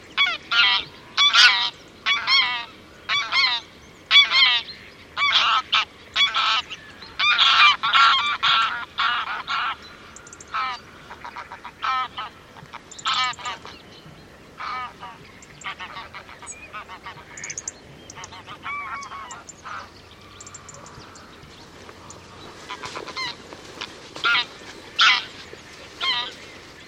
Oie cendrée - Mes zoazos
oie-cendree.mp3